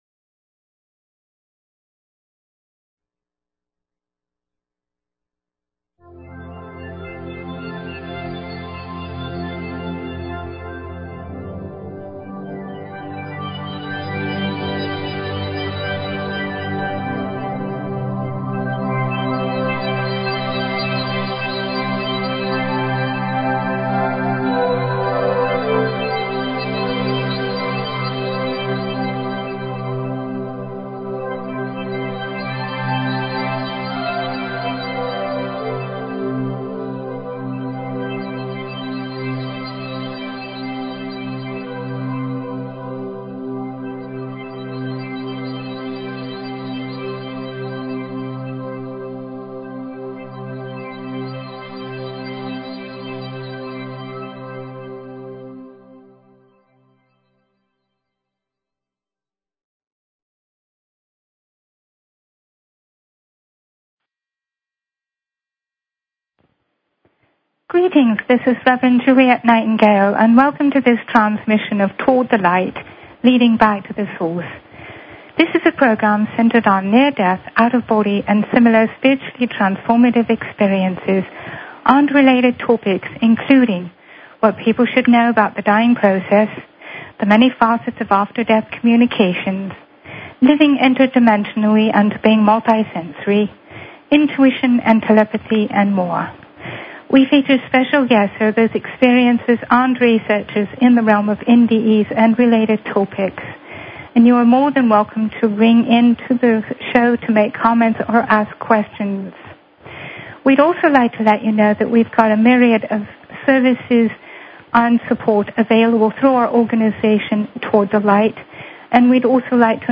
Talk Show Episode, Audio Podcast, Toward_The_Light and Courtesy of BBS Radio on , show guests , about , categorized as
From England, her accent immediately draws people in.